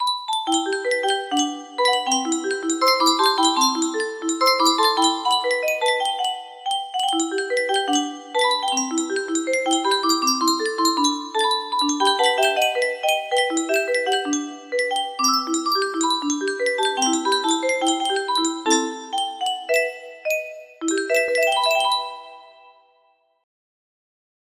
BDBD music box melody
Grand Illusions 30 (F scale)